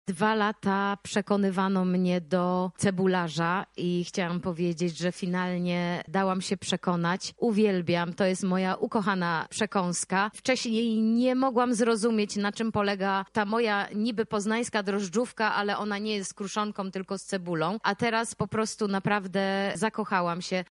-mówi Katarzyna Bujakiewicz.